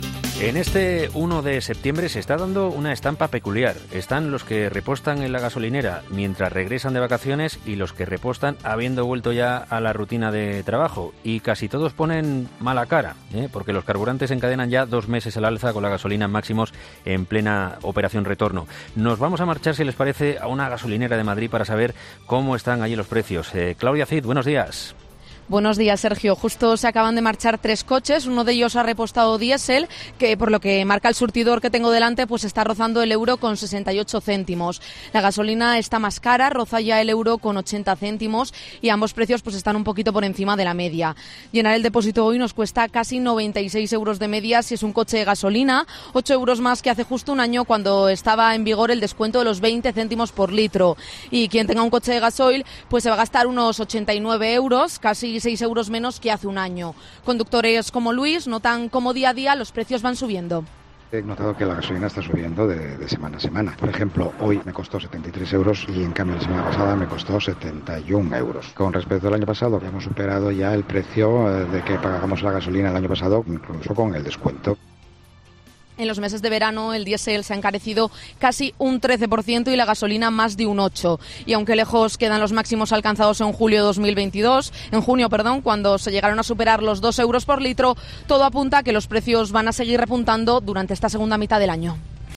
En esta gasolinera nos hemos encontrado con algunos conductores que están repostando y que nos cuentan que notan la subida de los precios día a día.